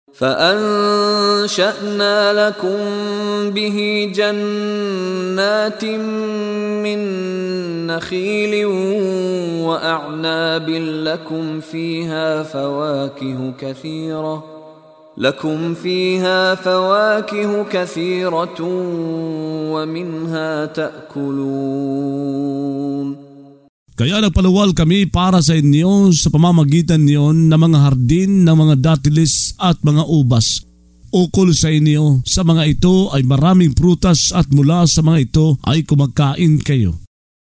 Pagbabasa ng audio sa Filipino (Tagalog) ng mga kahulugan ng Surah Al-Mu'minun ( Ang Mga Sumasampalataya ) na hinati sa mga taludtod, na sinasabayan ng pagbigkas ng reciter na si Mishari bin Rashid Al-Afasy. Ang paglilinaw sa tagumpay ng mga mananampalataya atpagkalugi ng mga tagatangging sumampalataya.